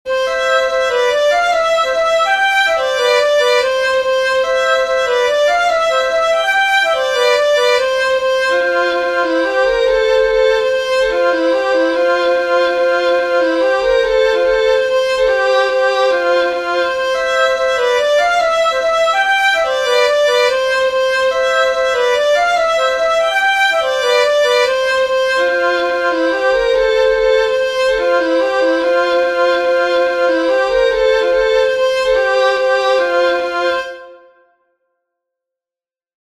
Musiche digitali in mp3 tratte dagli spartiti pubblicati su
Raccolta e trascrizioni di musiche popolari resiane